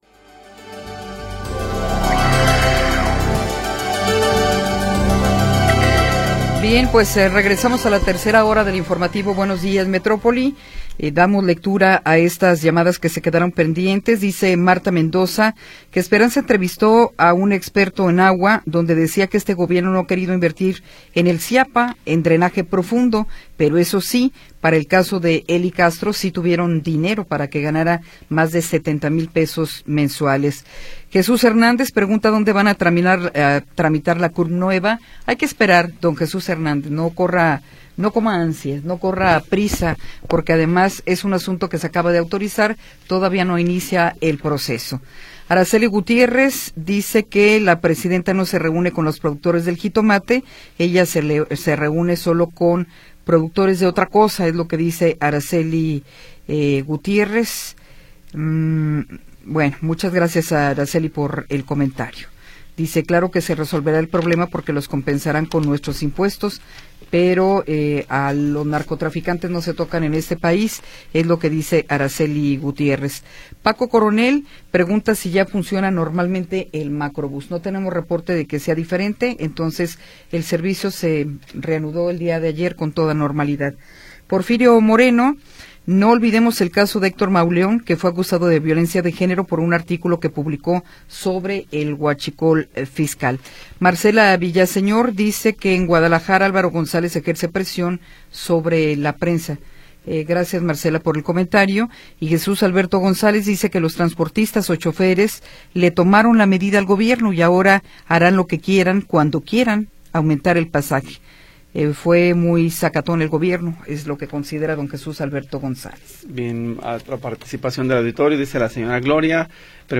Información oportuna y entrevistas de interés